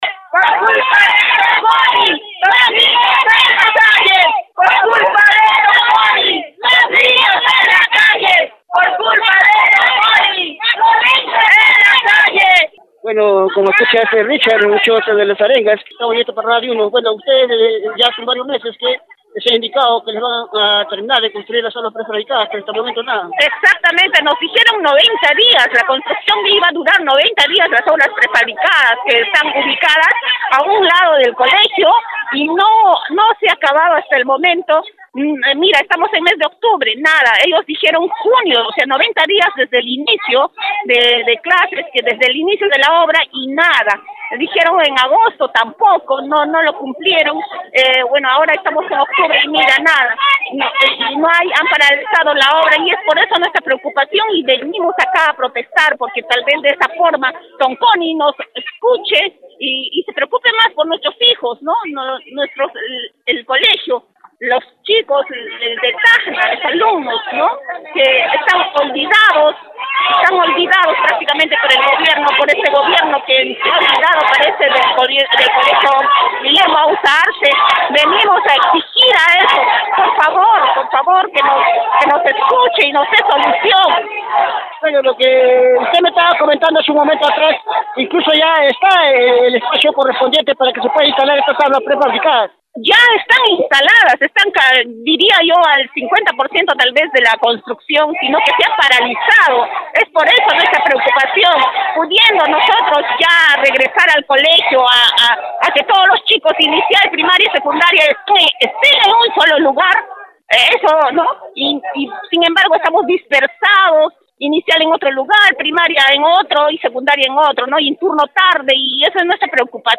desdxe-puerta-el-GRT-padre-sde-familia-dewl-IE-auza-arce.mp3